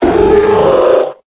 Cri de Séléroc dans Pokémon Épée et Bouclier.